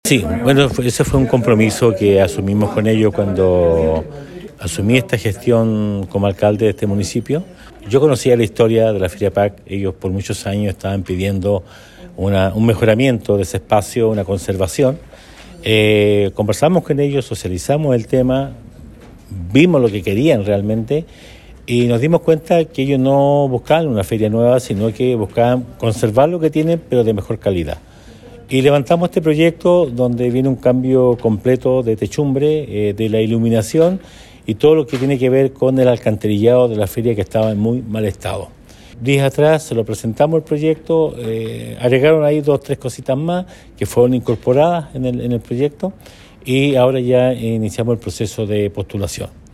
El jefe comunal expresó que el proyecto fue bien recepcionado por los feriantes, por lo que viene la etapa de postulación del proyecto de mejoramiento de casi 400 millones a los fondos de conservación.